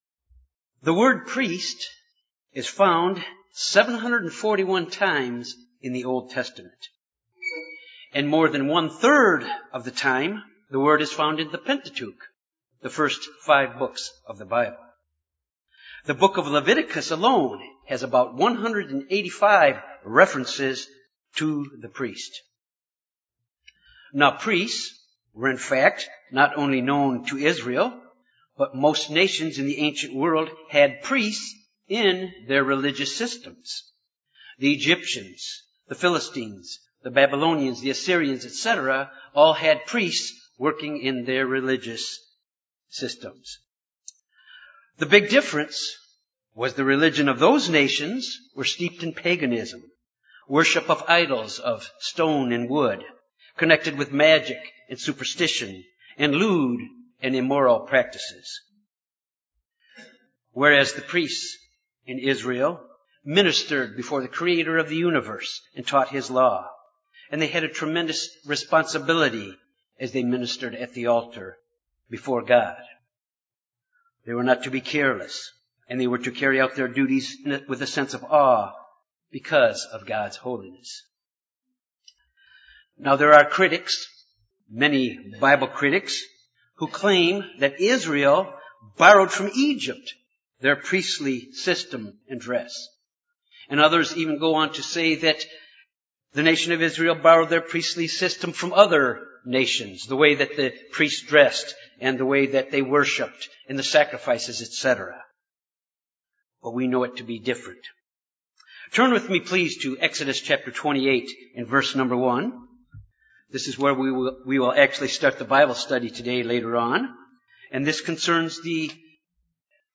Sermon
Given in Jonesboro, AR Little Rock, AR